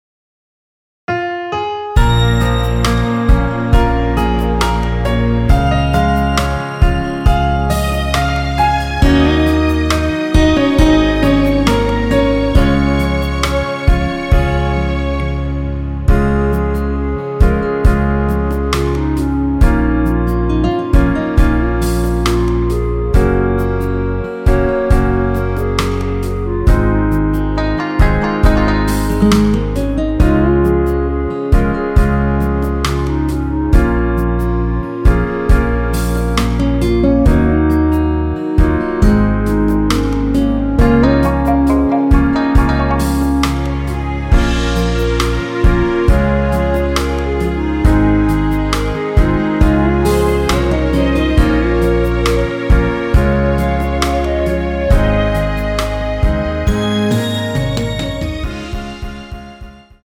원키에서(-2)내린 멜로디 포함된 MR입니다.
Db
앞부분30초, 뒷부분30초씩 편집해서 올려 드리고 있습니다.
중간에 음이 끈어지고 다시 나오는 이유는